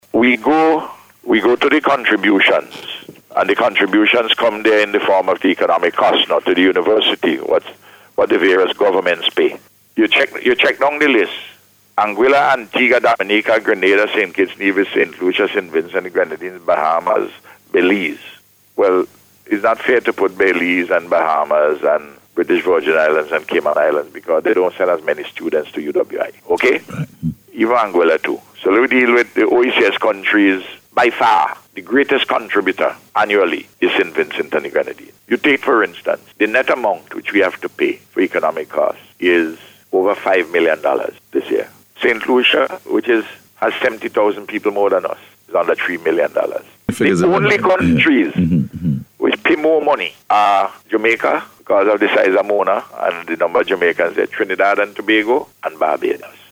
The Prime Minister made the point as he discussed the issue on NBC’s Face to Face programme this morning.